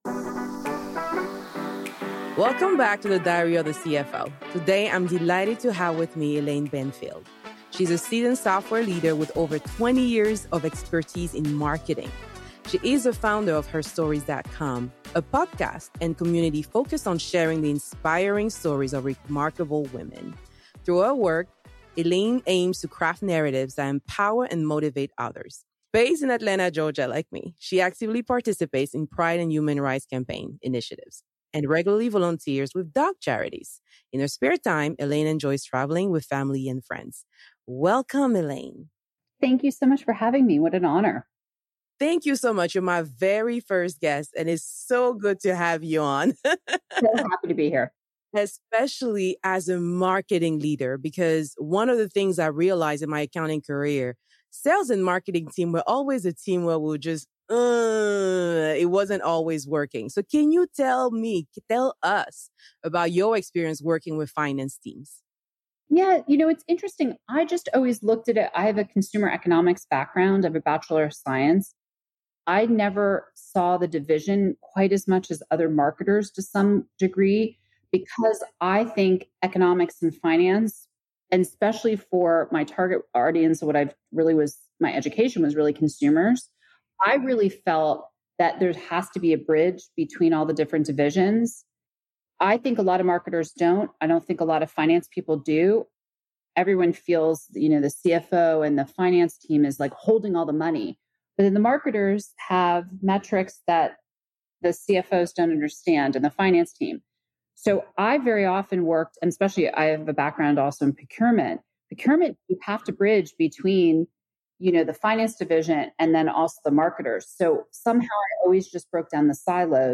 Introduction and Guest Welcome